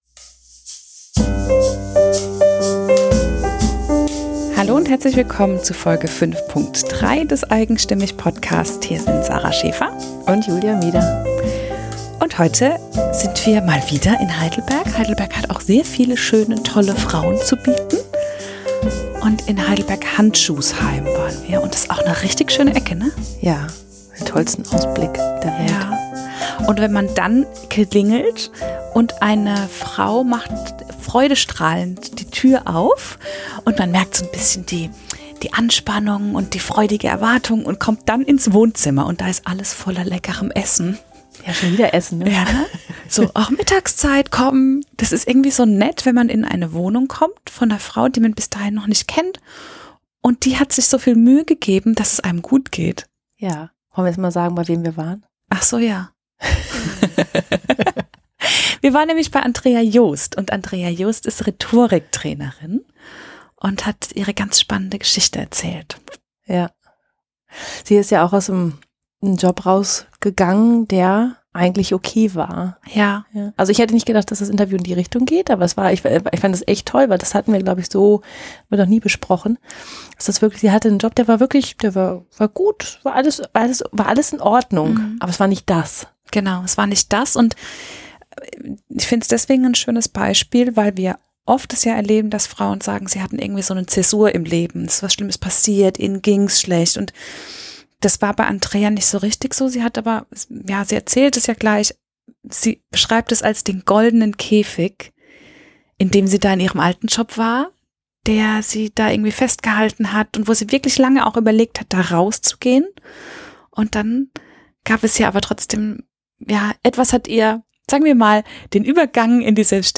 Was ihr den Einstieg erleichterte, verrät sie uns in diesem Interview, das ein bisschen ist wie sie selbst: herzlich und sprachlich auf den Punkt.
Interviews mit einzigartigen Frauen*, die lieben, was sie tun.